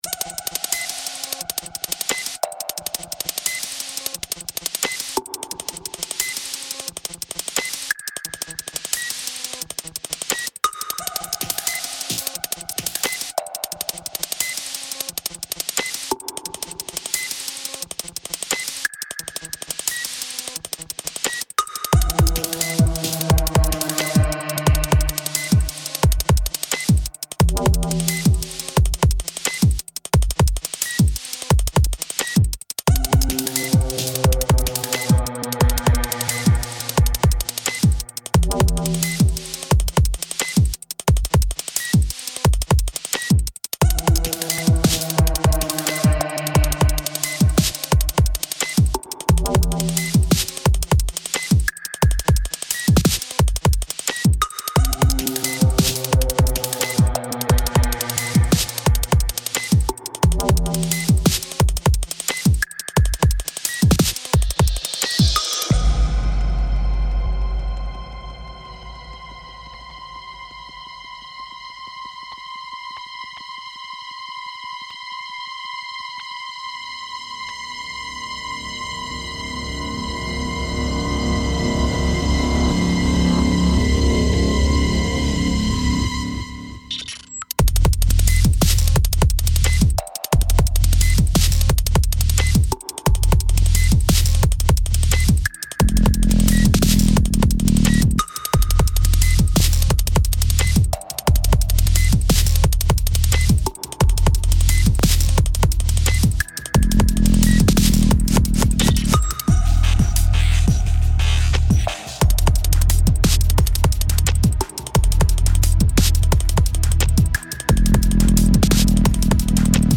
1hr of Deep & Mellow D&B Beats